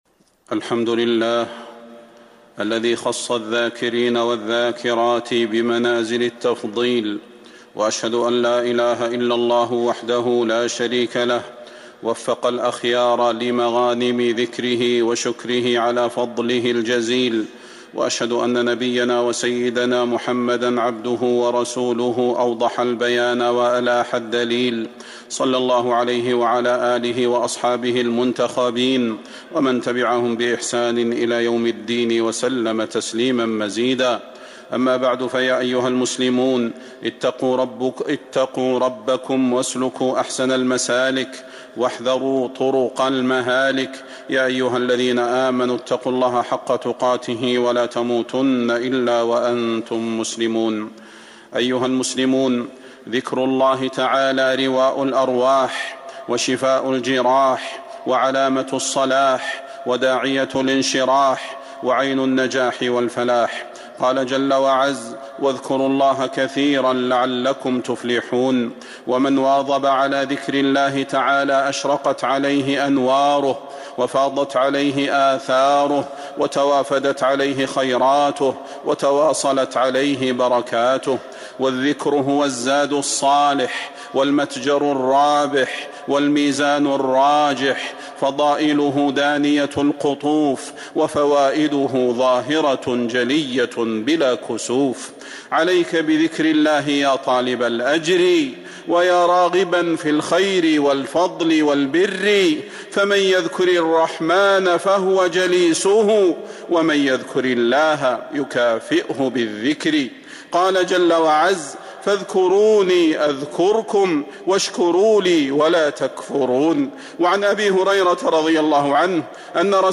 المدينة: ذكر الله عبادة الليل والنهار - صلاح بن محمد البدير (صوت - جودة عالية